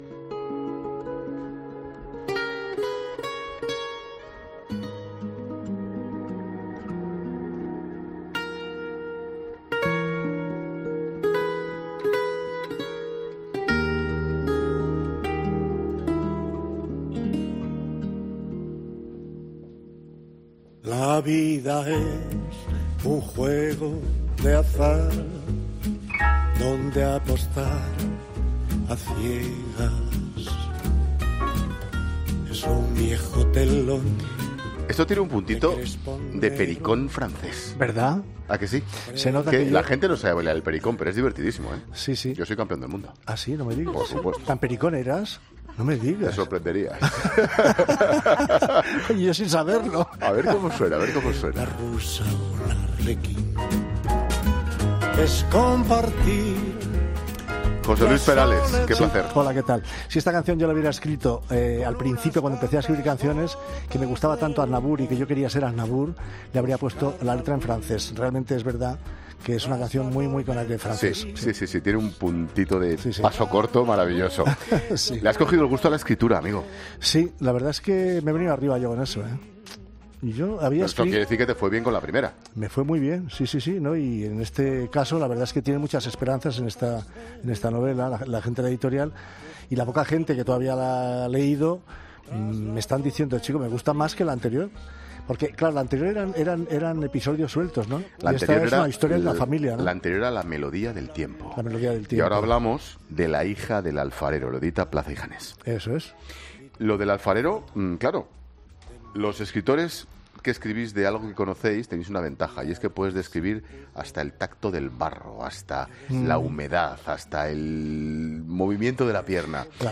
Escucha la entrevista a José Luis Perales en 'La Tarde'